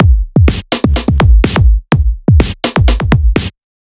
Сэмпл ударных (Брейкбит): Beat Break C
Sound_12272_BeatBreakC.ogg